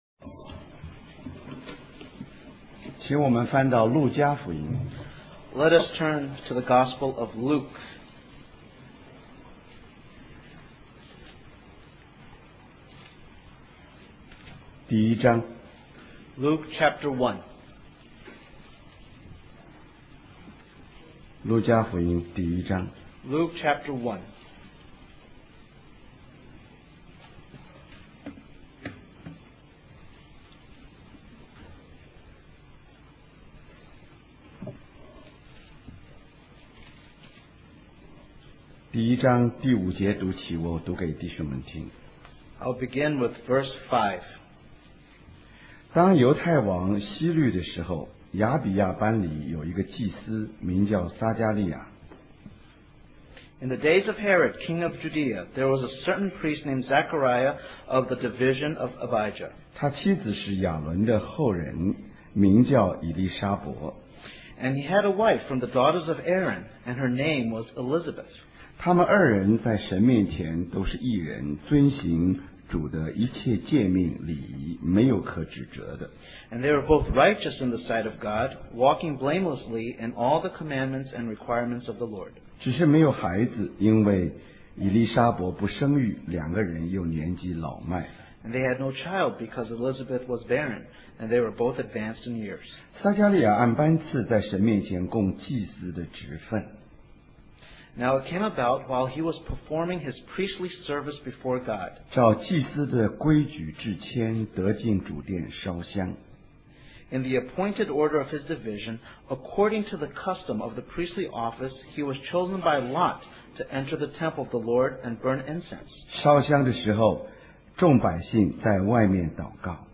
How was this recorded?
Special Conference For Service, Singapore